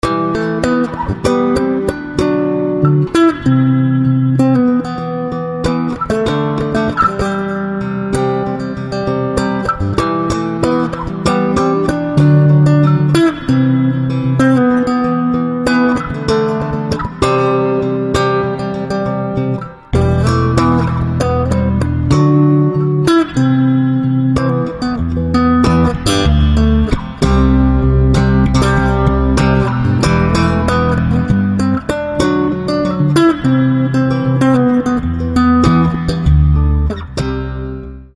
• Качество: 320, Stereo
без слов
инструментальные
приятные
New Age
бас-гитара
гитарное соло
Мелодичное соло на бас-гитаре.